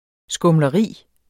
Udtale [ sgɔmlʌˈʁiˀ ]